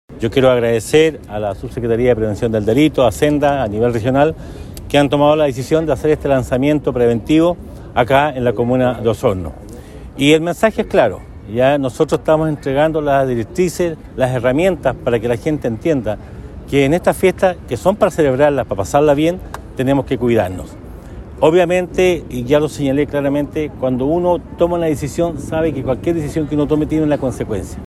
El Alcalde de la comuna de Osorno, Emeterio Carrillo, entregó folletería informativa  a los automovilistas que circulaban por la Plaza de Armas, con consejos para un manejo responsable y seguro durante Fiestas Patrias, ocasión en la que indicó que estas herramientas permitirán a la comunidad tomar conciencia ante el autocuidado.